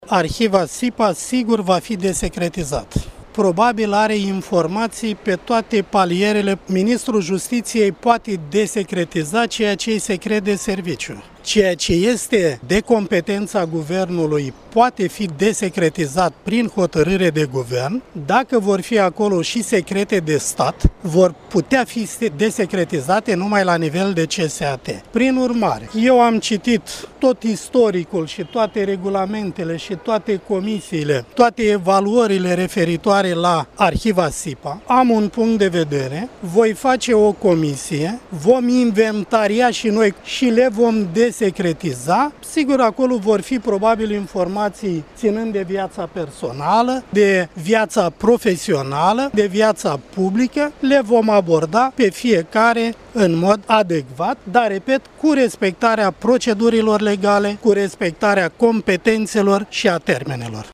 Tudorel Toader a mai spus că personal şi-a format un punct de vedere şi există posibilitatea ca în respectivele arhive să fie găsite informaţii despre magistraţi care  privesc viaţa lor personală, profesională sau publică: